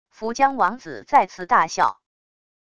伏江王子再次大笑wav音频